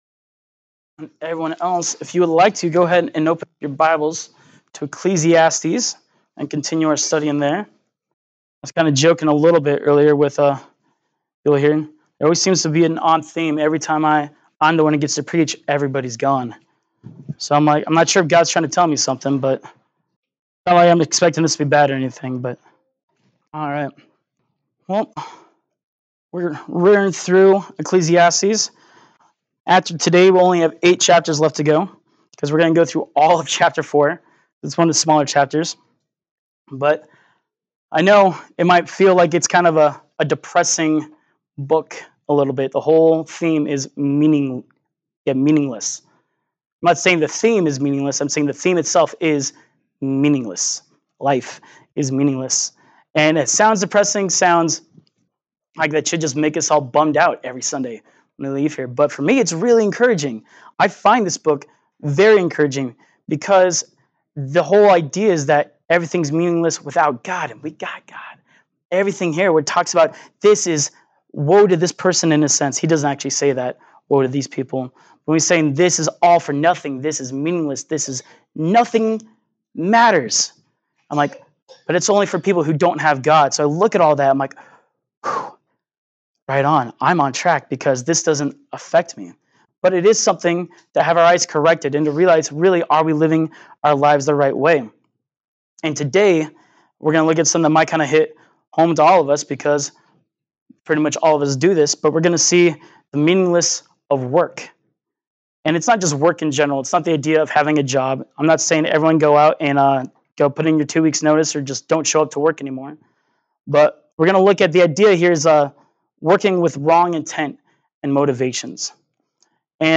Ecclesiastes 4 Service Type: Sunday Morning Worship « Ecclesiastes 3:1-15